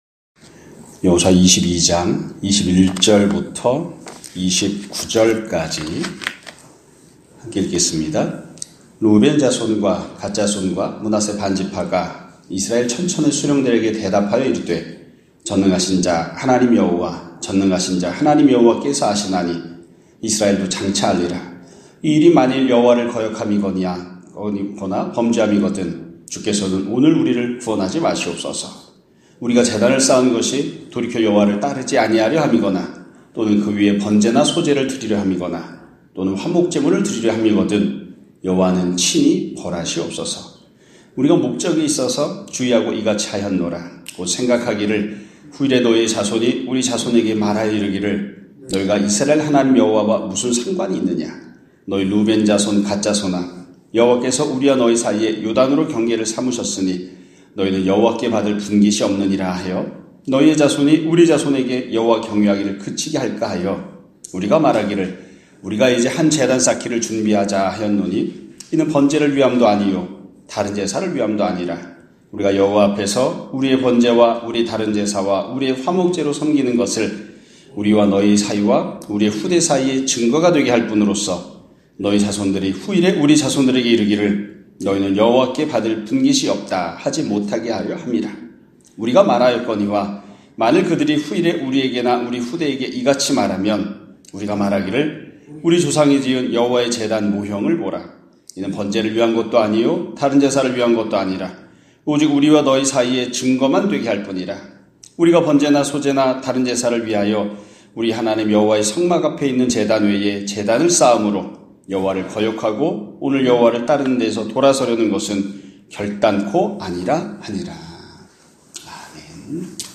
2025년 2월 12일(수요일) <아침예배> 설교입니다.